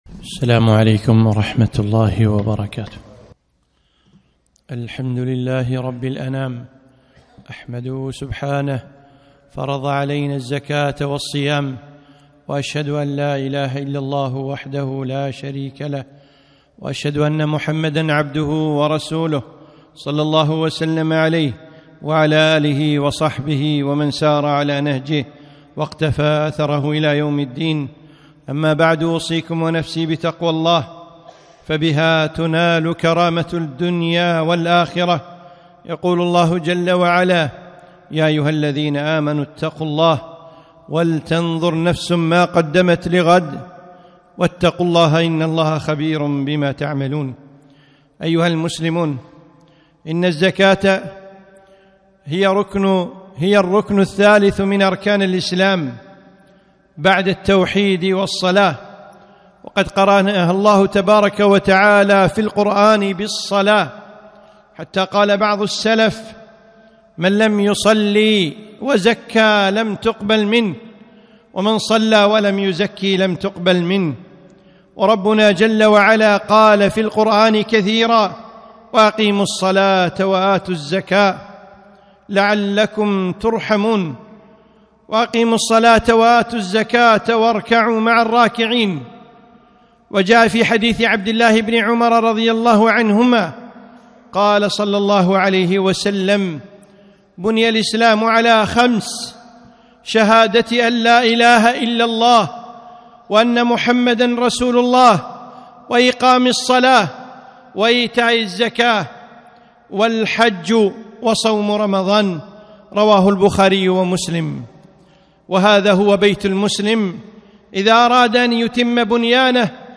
خطبة - زكاة المال